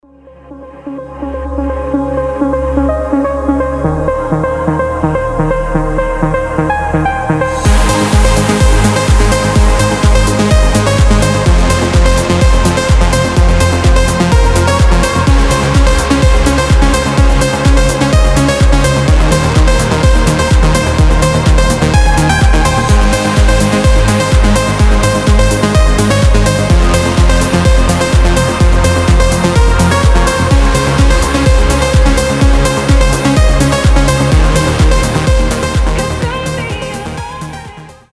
• Качество: 320, Stereo
громкие
мелодичные
EDM
транс